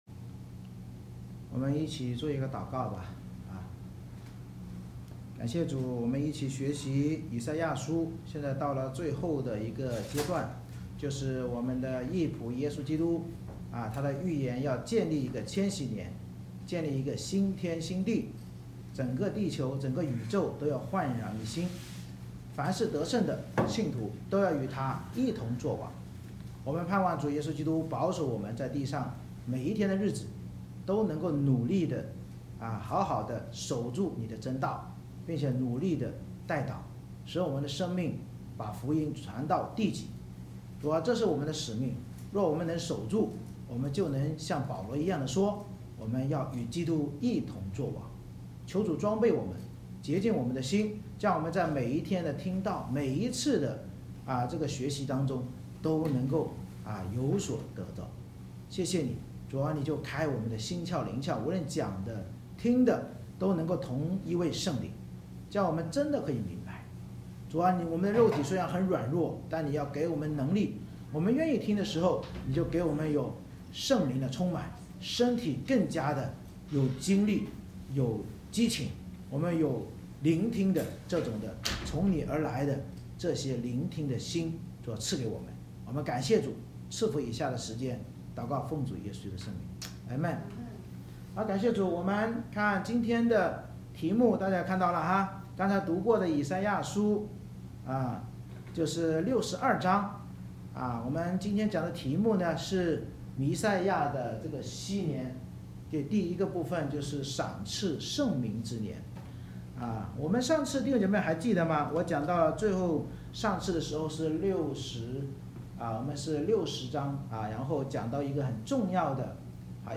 以赛亚书Isaiah 62:1-12 Service Type: 主日崇拜 神藉着先知预言受苦弥赛亚将来所治理的禧年，激励我们只要在属神的教会中昼夜守望代祷，到弥赛亚再临之时必得赏赐与称赞。